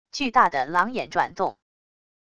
巨大的狼眼转动wav下载